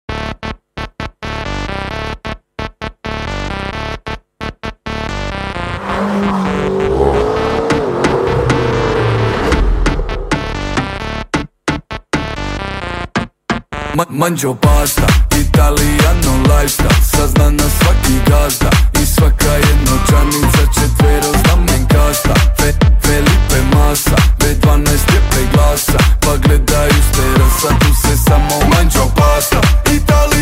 Motor urla kroz grad.